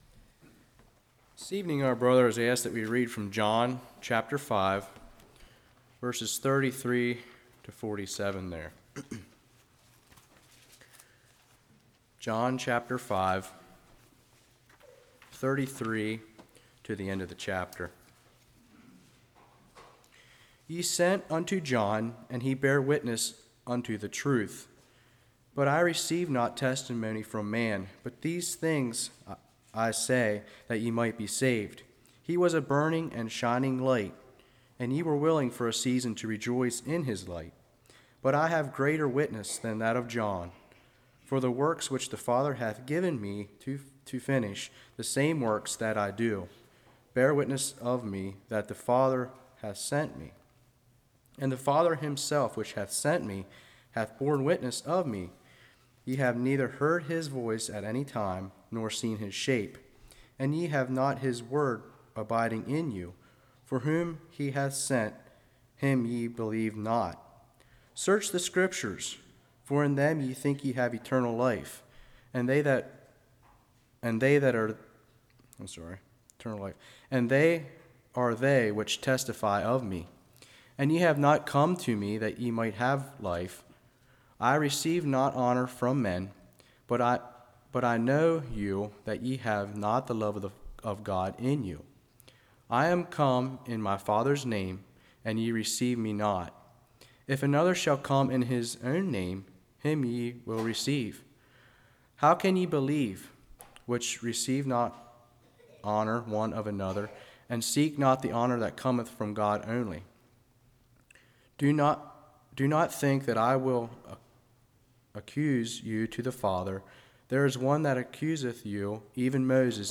John 5:33-47 Service Type: Evening Jesus